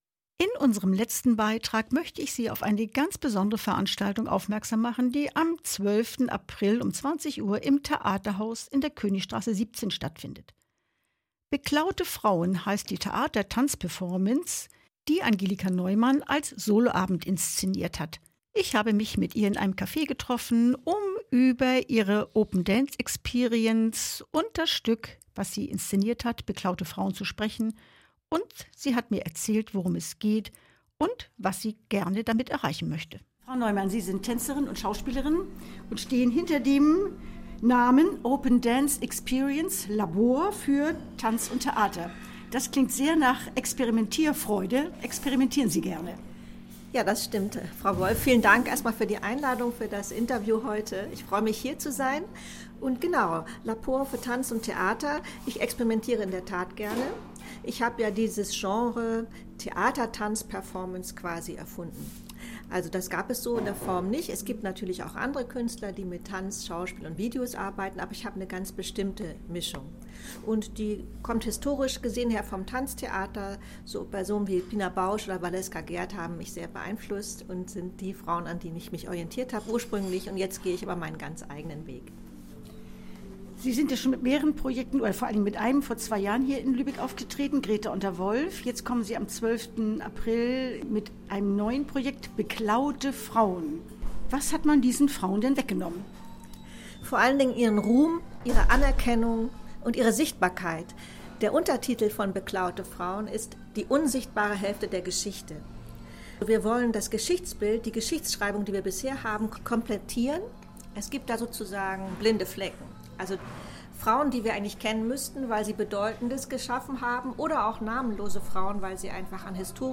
Kulturmelange - Gespräch über "Beklaute Frauen"